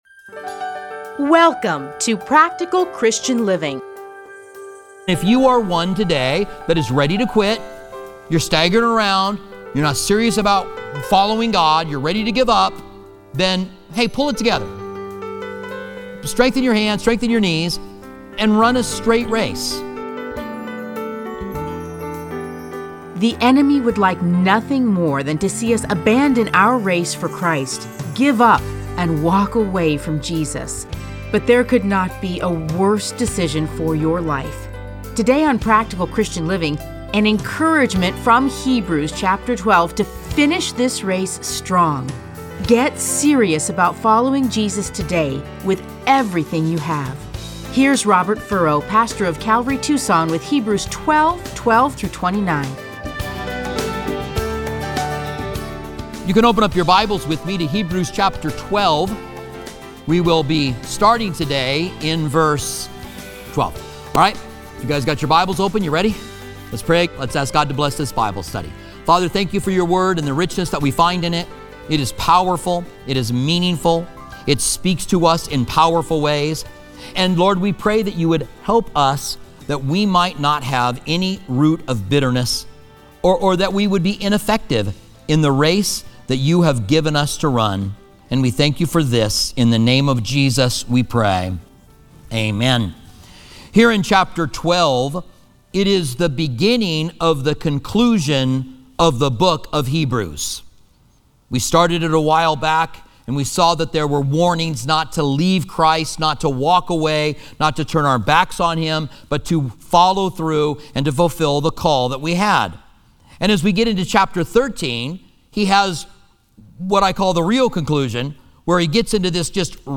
Listen to a teaching from Hebrews 12:12-29.